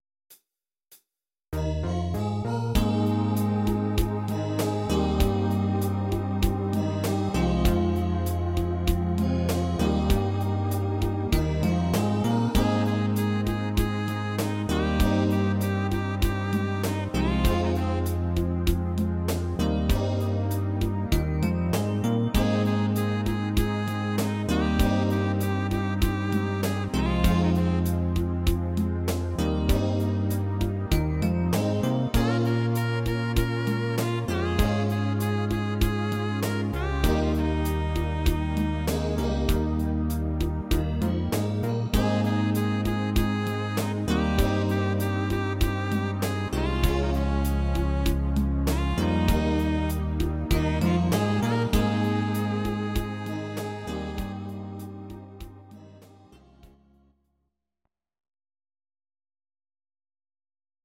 Audio Recordings based on Midi-files
Pop, Musical/Film/TV, 1980s